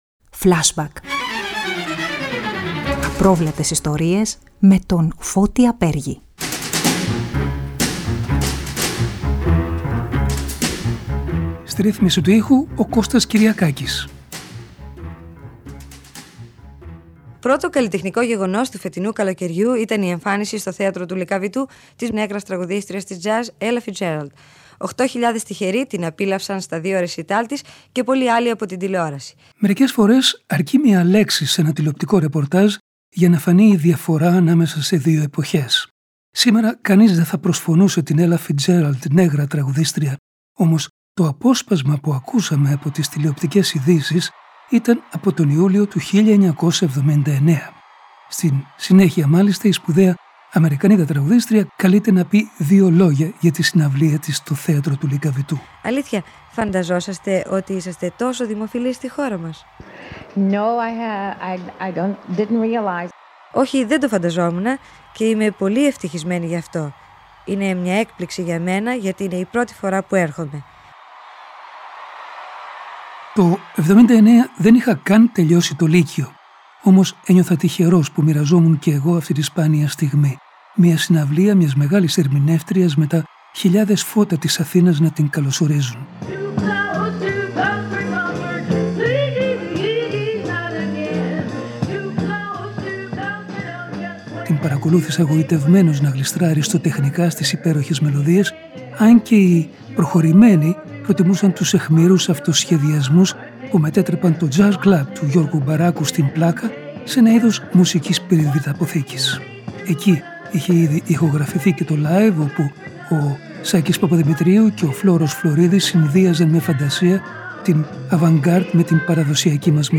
Και αποσπάσματα από συνεντεύξεις του Μπράιαν Φέρι και του Λούτσιο Ντάλα προς τον δημοσιογράφο, με αφορμή τις δικές τους εμφανίσεις.